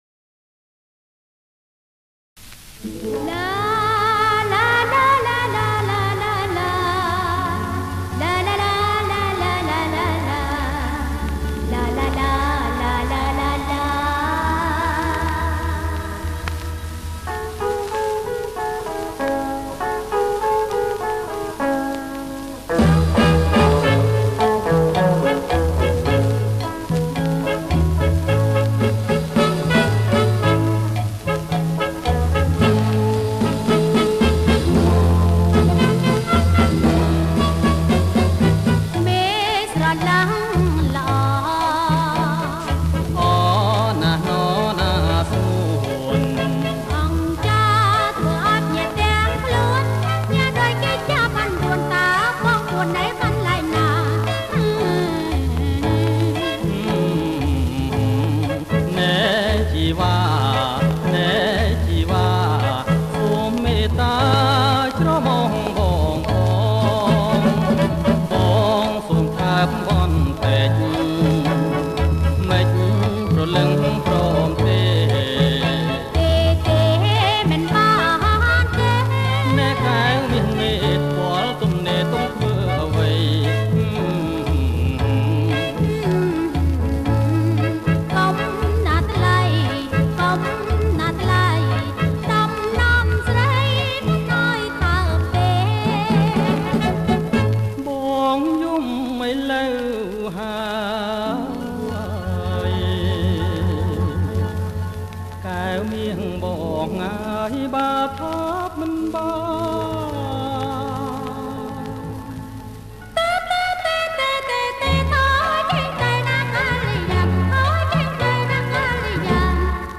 • ប្រគំជាចង្វាក់ Cha Cha Cha
ប្រគំជាចង្វាក់  CHA CHA CHA